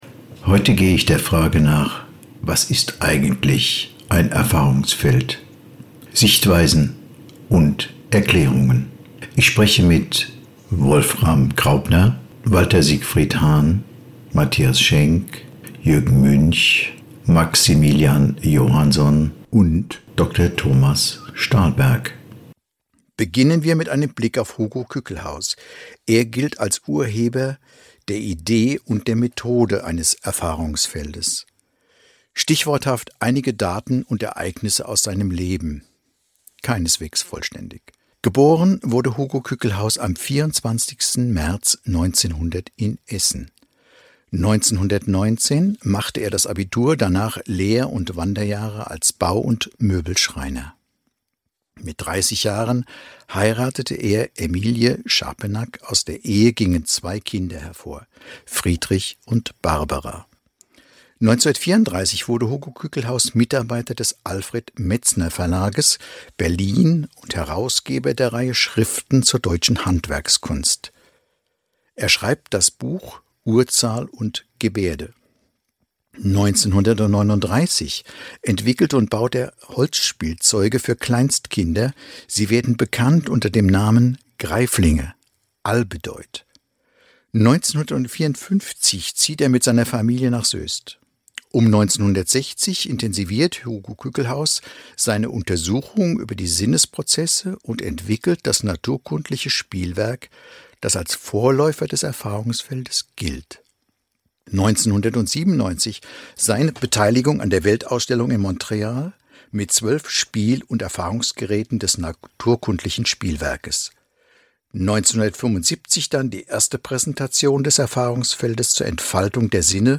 Der Verkümmerung der Sinne des Menschen entgegenzuwirken, gilt als eine seiner Intensionen zur Entwicklung des naturkundlichen Spielwerks, dem Versuchsfeld zur Organerfahrung und dem heutigen Erfahrungsfeld zur Entwicklung der Sinne und des Denkens. Auszüge aus Gesprächen mit